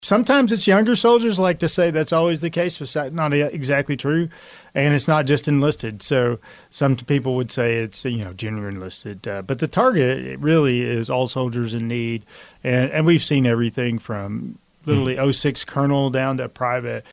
The Manhattan Area Chamber of Commerce held its monthly military relations luncheon on Friday with its featured speaker being retired Sergeant Major of the Army Michael Tony Grinston who was recently appointed the CEO of Army Emergency Reserve.